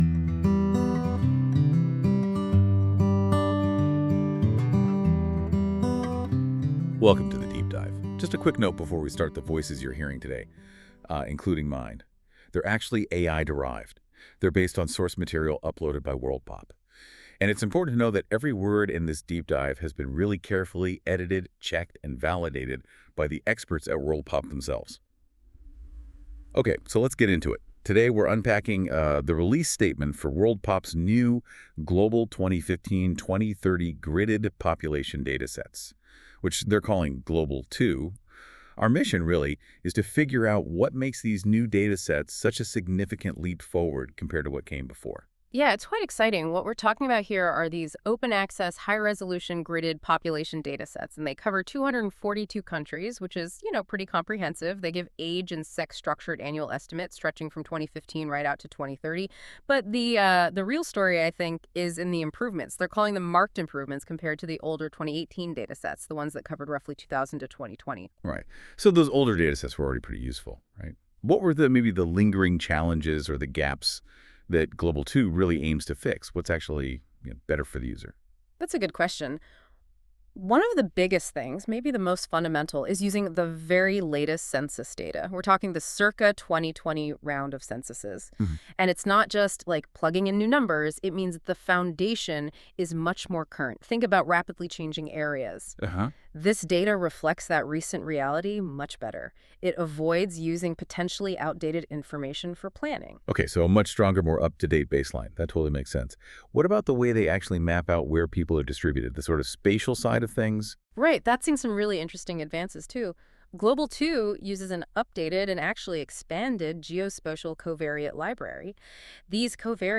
This feature uses AI to create a podcast-like audio conversation between two AI-derived hosts that summarise key points of a document - in this case our Global 2 population data Release Statement R2025A V1.
Music: My Guitar, Lowtone Music, Free Music Archive (CC BY-NC-ND)